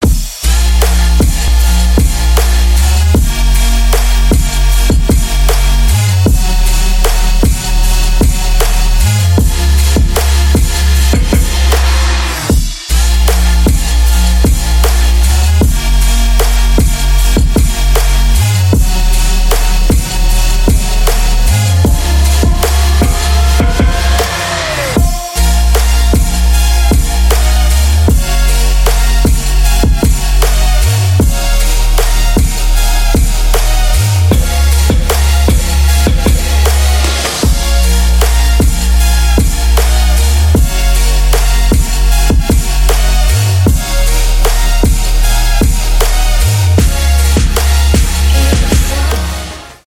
trap
future bass